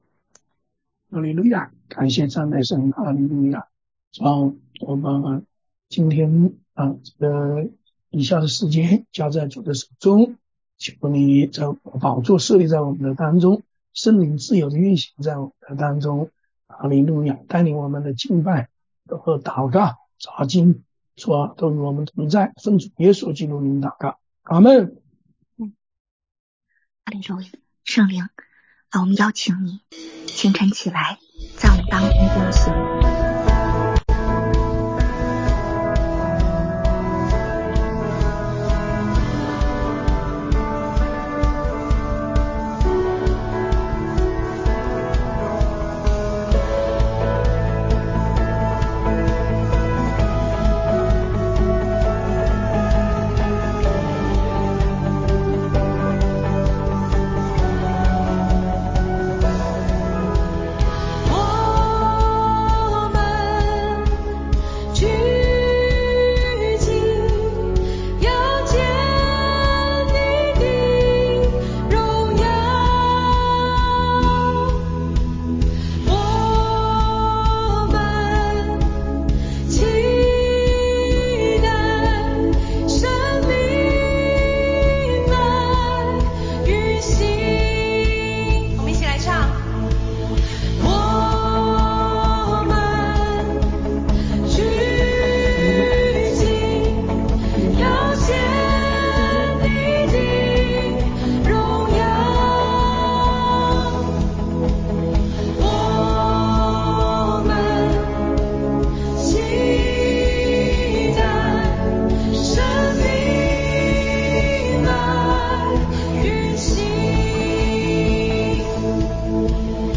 晨祷